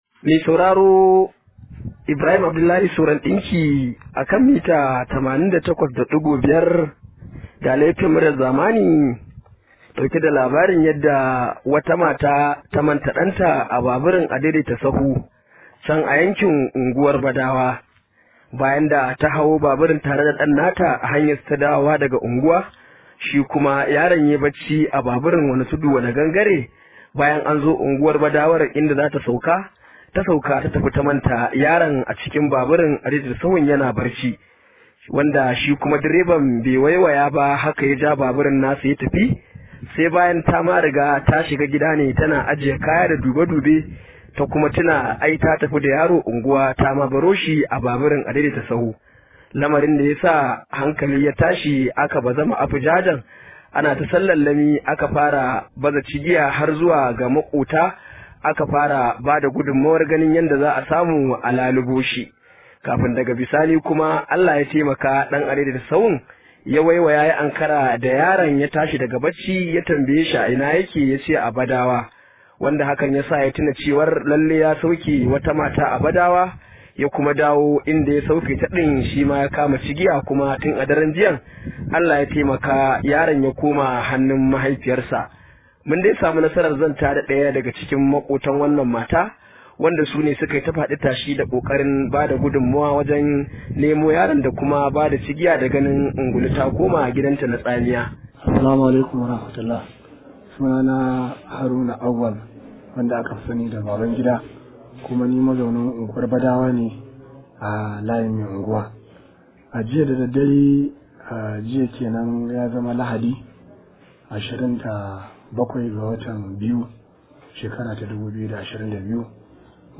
Rahoto: Sai da na koma gida na tuna na manta ɗa na a Adaidaita sahu – Wata mata
ya zanta da matar